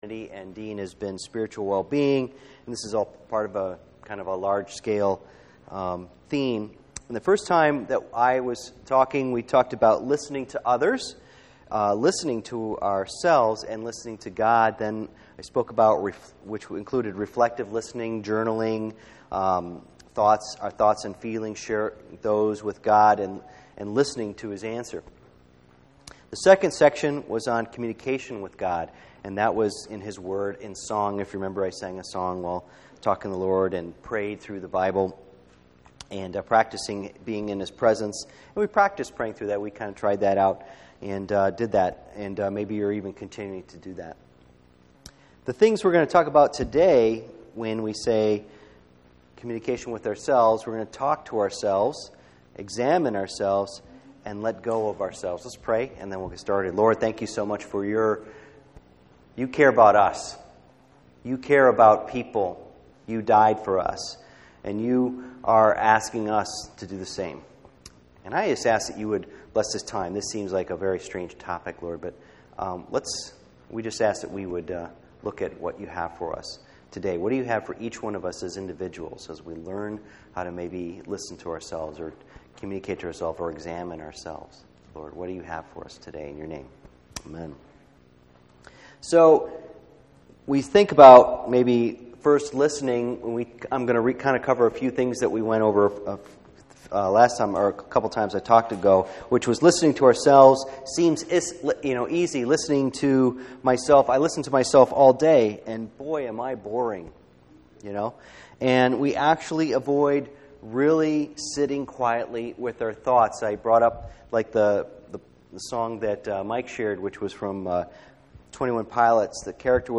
Ambasssadors in Polarized World Service Type: Sunday Morning %todo_render% « Spiritual Identity